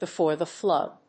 アクセントbefòre the Flóod